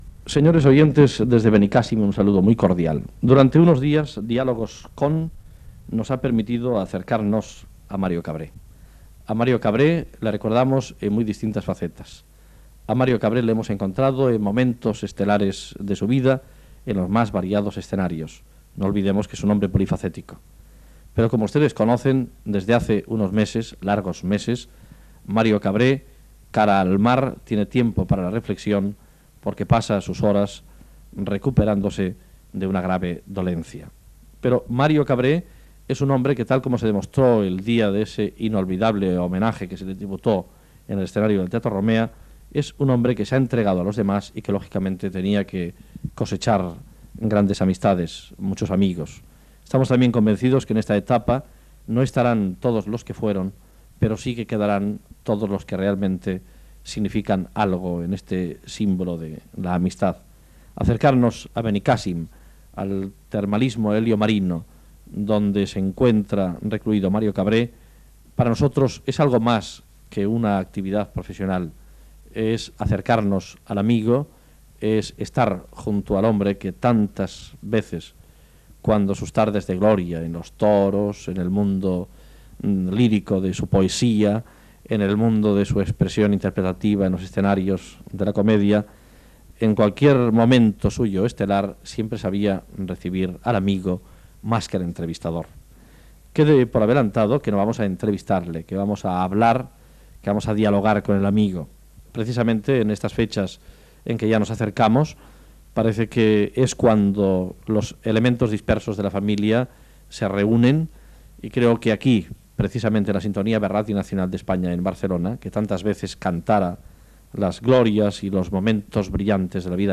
Presentació i entrevista a l'actor català Mario Cabré, que també va ser torero, presentador de televisió i poeta.
Fragment extret del programa "L'altra ràdio", de Ràdio 4, procedent de l'Arxiu Sonor de RNE